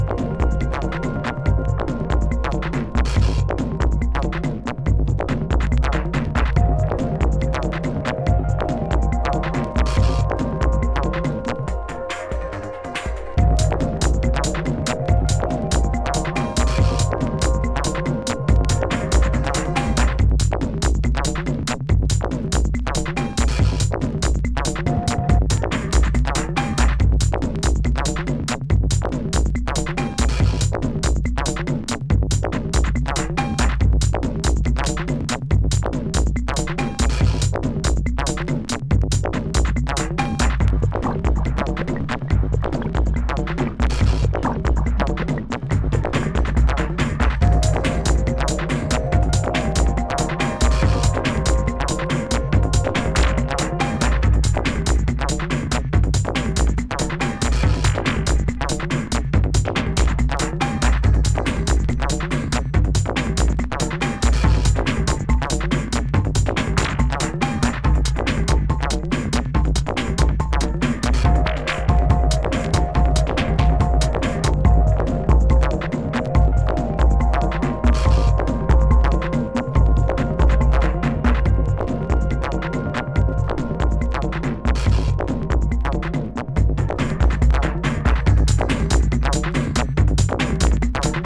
どのトラックも茶目っ気＆毒気ありのElectroトラック！